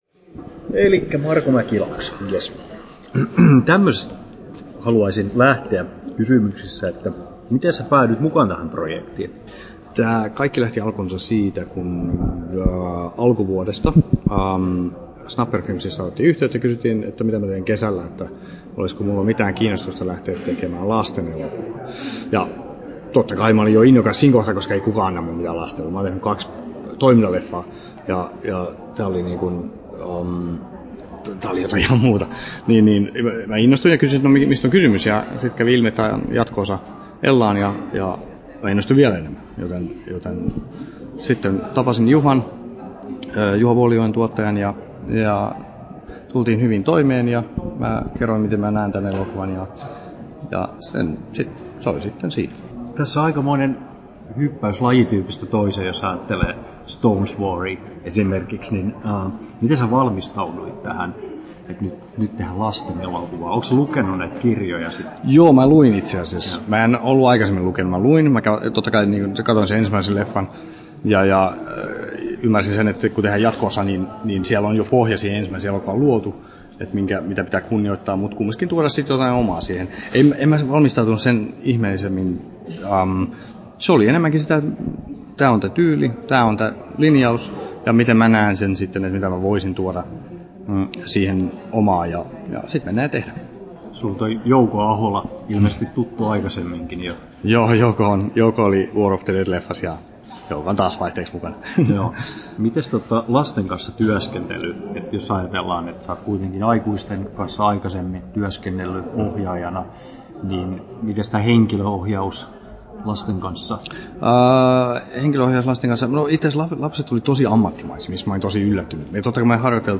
haastattelu
Turku Toimittaja